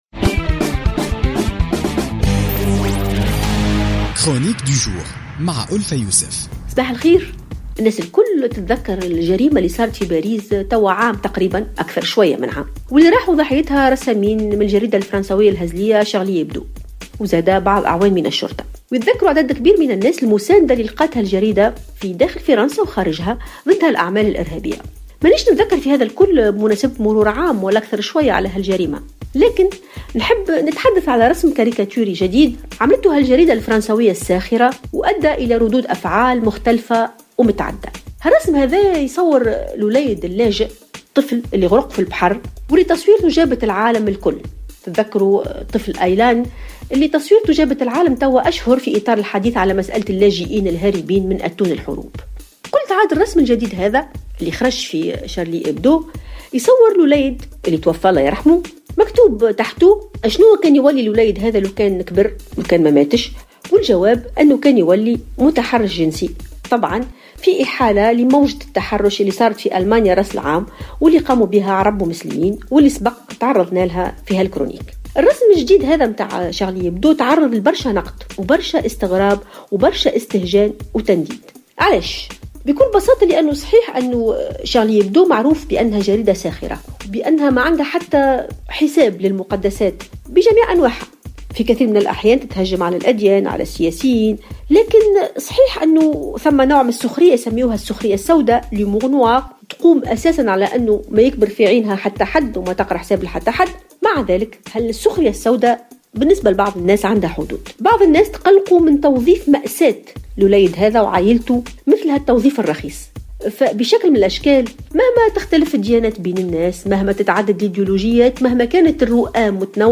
تطرقت الأستاذة الجامعية ألفة يوسف في افتتاحية اليوم الجمعة 5 فيفري 2016 إلى الرسم الكاريكاتوري الذي نشرته شارلي ايبدو الفرنسية حول الطفل اللاجئ الغريق "آيلان" والذي صور الطفل مرفوقا بتعليق يقول أنه لو لم يغرق لأصبح متحرشا جنسيا عندما يكبر.